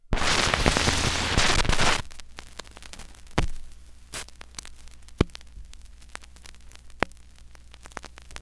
ScratchAndPop
pop record scratch static vinyl sound effect free sound royalty free Sound Effects